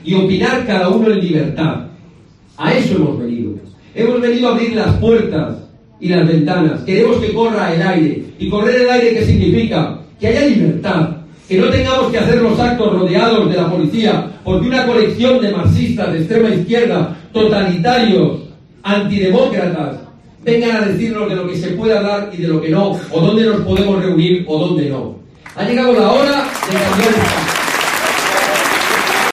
JAVIER ORTEGA SMITH MITIN